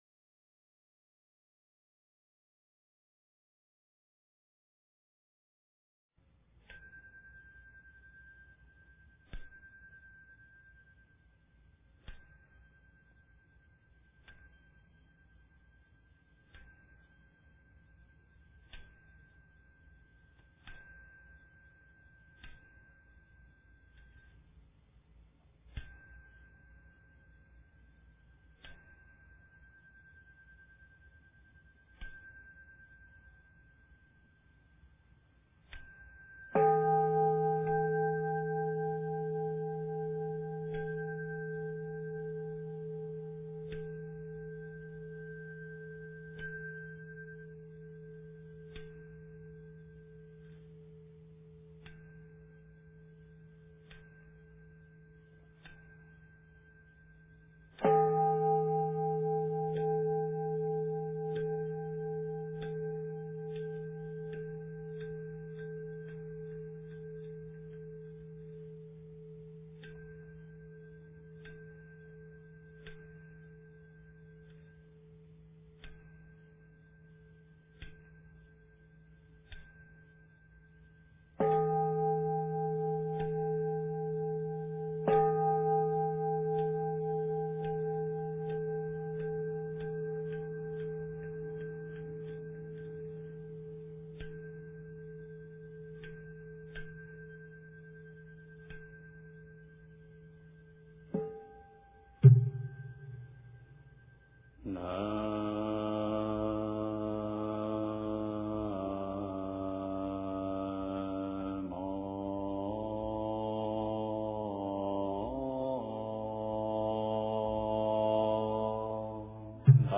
禅修早课--法鼓山 经忏 禅修早课--法鼓山 点我： 标签: 佛音 经忏 佛教音乐 返回列表 上一篇： 南无阿弥陀佛--印光大师版 下一篇： 忏悔文--女声 相关文章 礼88佛大忏悔文--佚名 礼88佛大忏悔文--佚名...